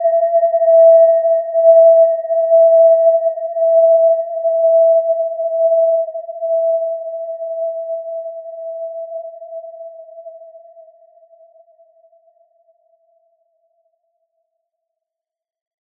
Gentle-Metallic-3-E5-p.wav